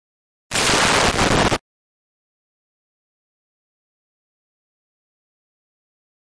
Liquid Hit Electronic Rip with Static